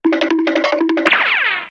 Fx Correr Animado Sound Button - Free Download & Play